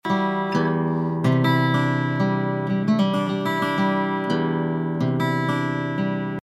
Ленточный микрофон "АПЛ" (Алюминиевая-Поталевая-Лента) лента 0,2 микрона.
Второй АПЛ с более выраженными низкими частотами из той же одновременной записи: Микрофон продается в том числе тут...